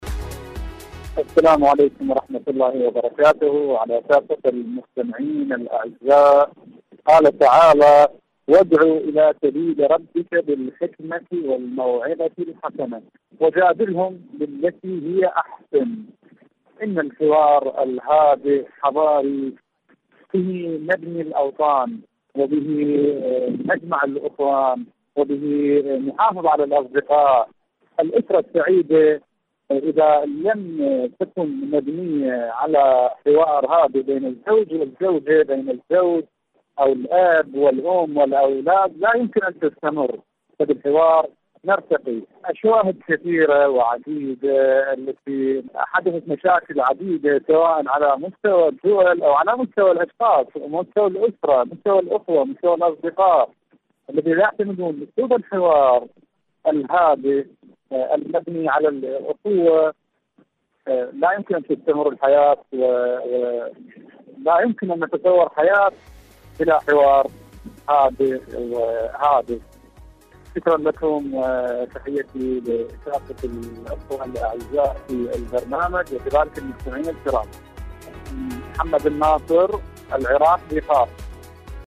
دنيا الشباب / مشاركة هاتفية